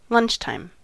Uttal
Alternativa stavningar lunch-time lunch time Synonymer breaktime Uttal US Ordet hittades på dessa språk: engelska Ingen översättning hittades i den valda målspråket.